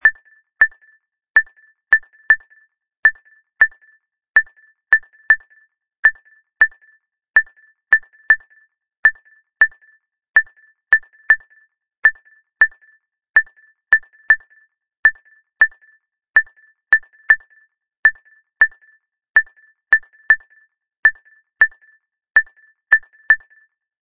Ces deux simples morceaux de bois vont guider toute la formation musicale.
La clave rumba, parfois appelée clave negra, ne diffère de la clave son que d'une note.
Clave rumba 3/2
clave_rumba_32.mp3